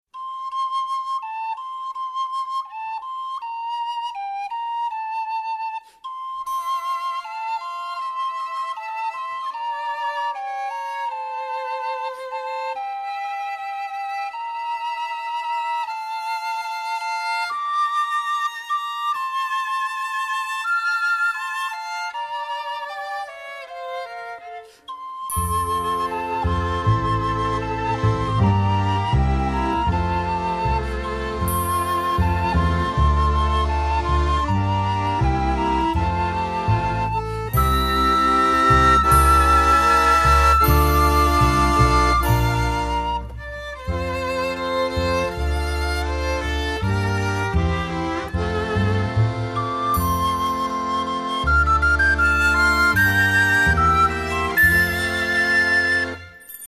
Konzertante Lieder und Weihnachtslieder aus der Ukraine und Deutschland
Instrumental "Quellen"